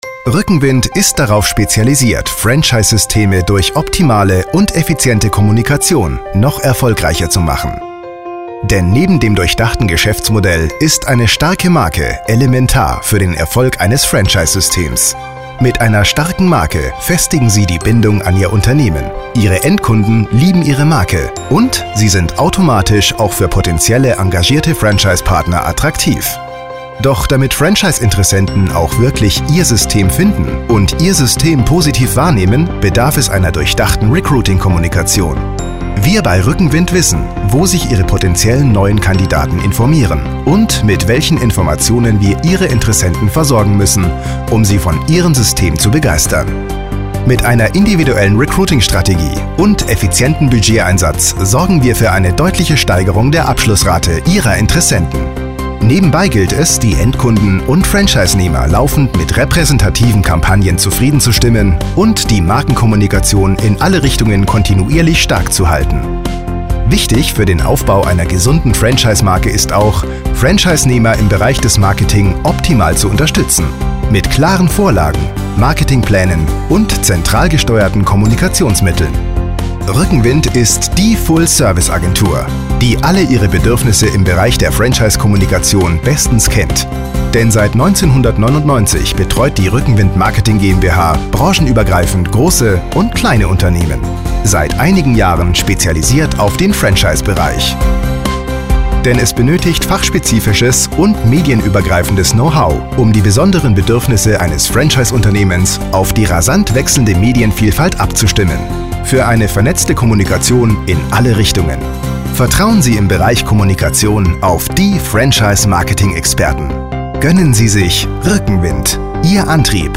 Deutscher Sprecher, Off-Sprecher, Werbesprecher, Stationvoice, Radiowerbung, Funkwerbung, Fernsehwerbung, Industriefilm, eLearning, Imagefilm
Sprechprobe: Industrie (Muttersprache):